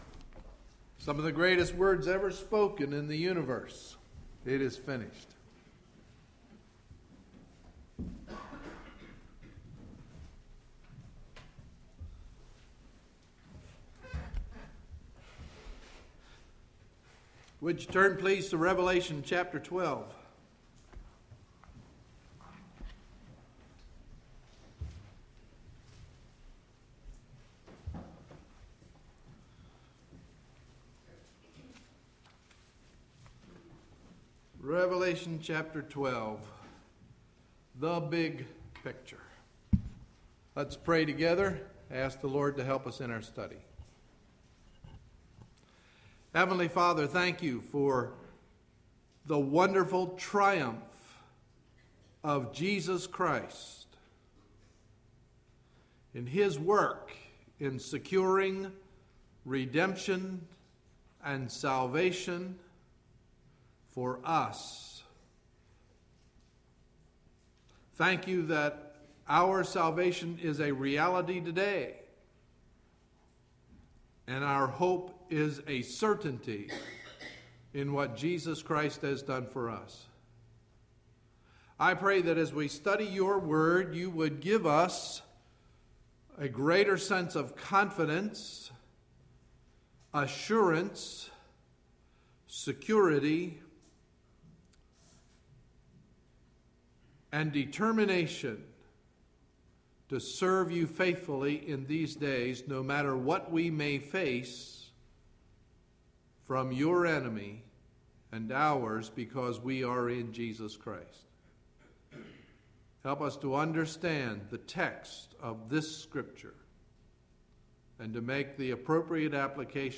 Sunday, September 18, 2011 – Morning Message